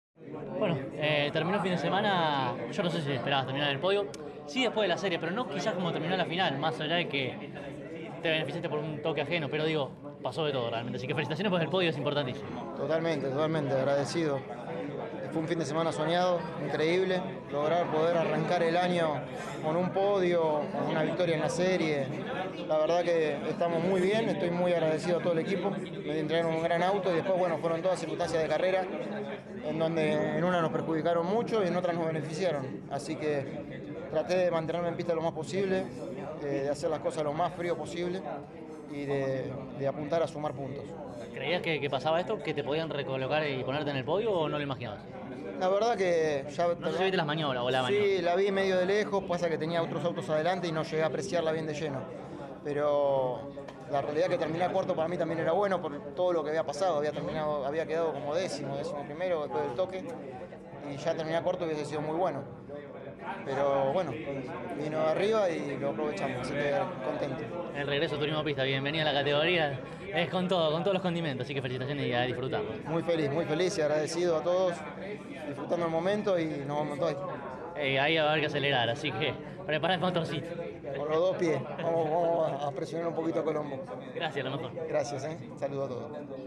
CÓRDOBA COMPETICIÓN estuvo presente en el trazado platense y dialogó con los protagonistas más importantes al cabo de cada la final de la clase mayor.